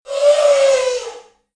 Elephant Scream Bouton sonore